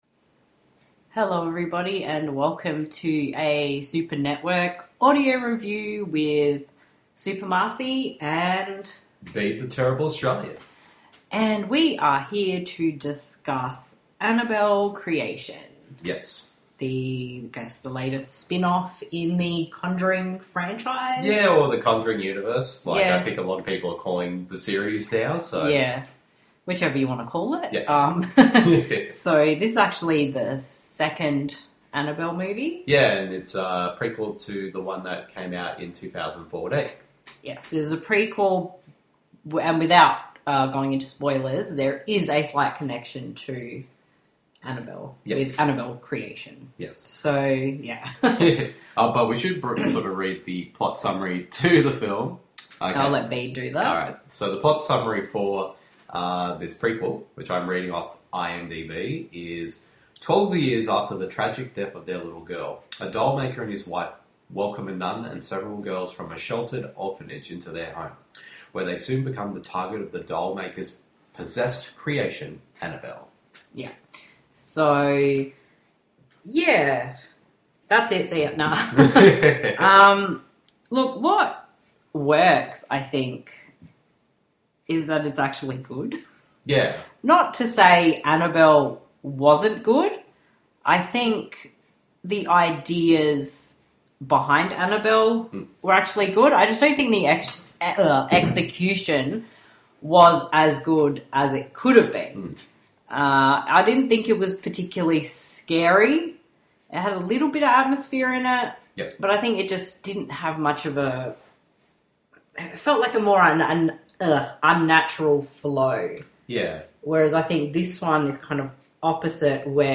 annabelle-creation-audio-review.mp3